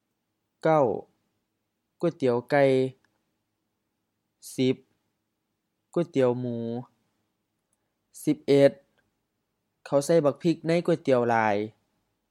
กวยเตียว gu:ai-ti:ao M-M ก๋วยเตี๋ยว kind of noodle soup
Notes: pronunciation: often with rising tones, especially in isolation